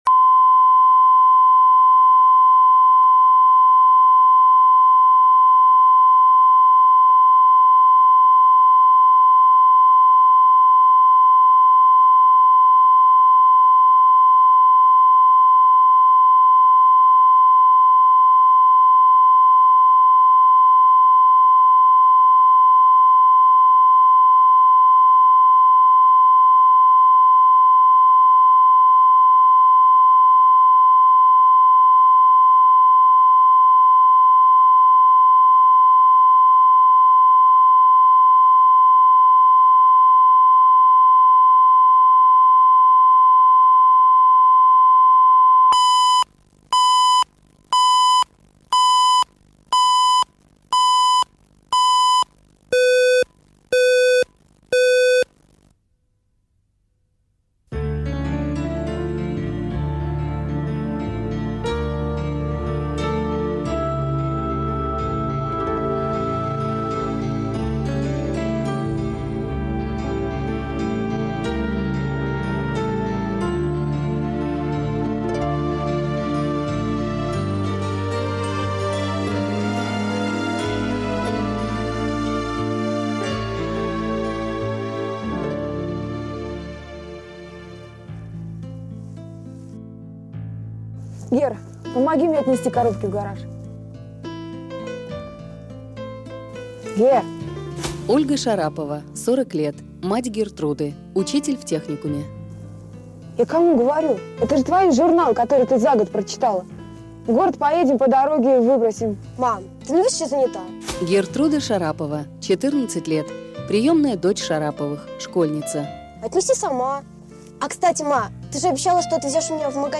Аудиокнига Вредная дочь | Библиотека аудиокниг